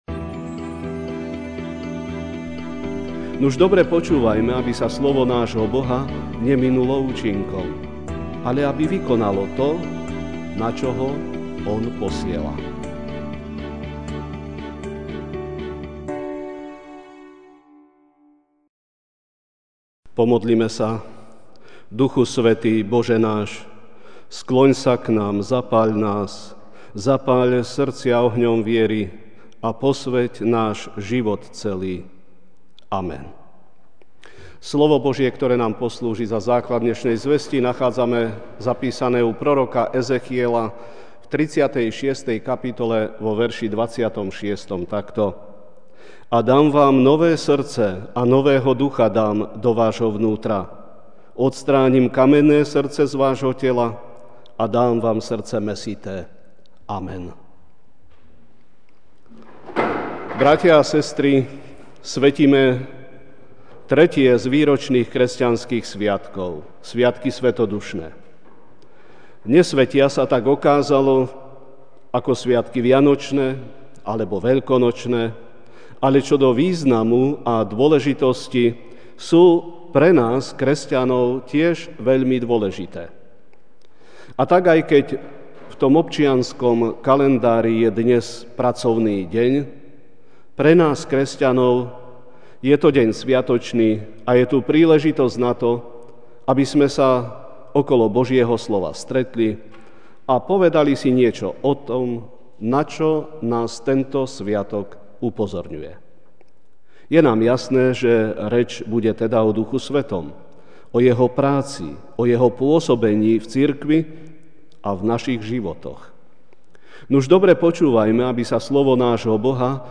Večerná kázeň: Srdce z kameňa (Ez 36, 26) Dám vám nové srdce a nového ducha do vášho vnútra; odstránim kamenné srdce z vášho tela a dám vám srdce mäsité.